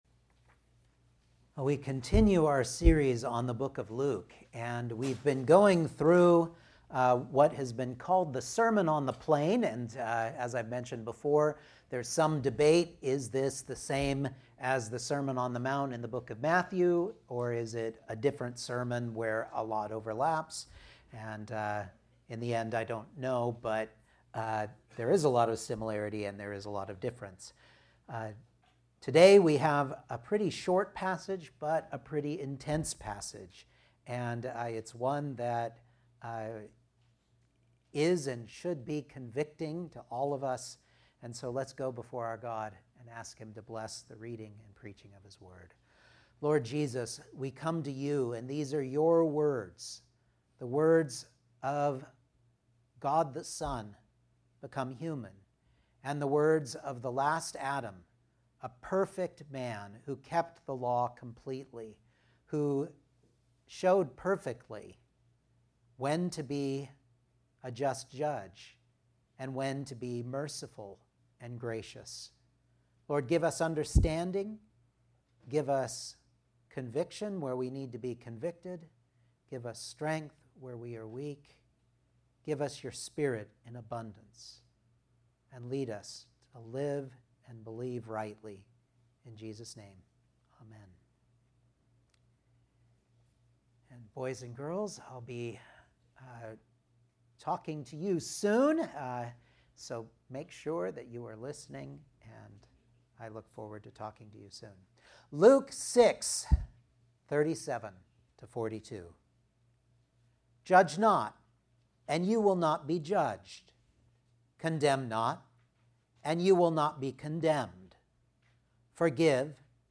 Luke 6:37-42 Service Type: Sunday Morning Outline